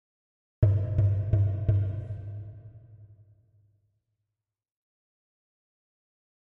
Drums Hits 2 - Four Lower Hits